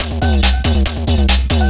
DS 140-BPM B5.wav